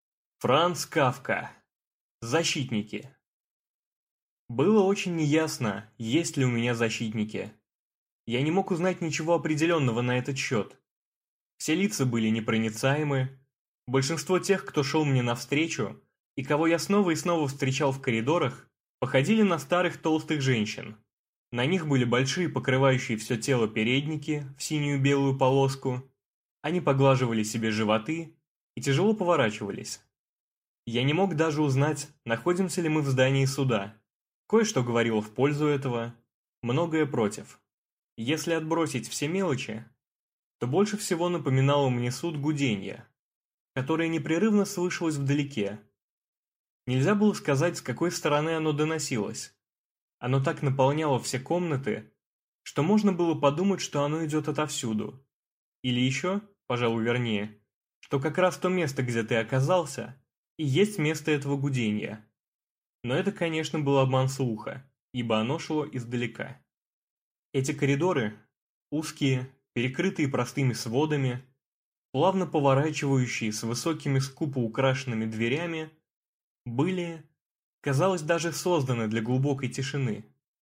Аудиокнига Защитники | Библиотека аудиокниг
Прослушать и бесплатно скачать фрагмент аудиокниги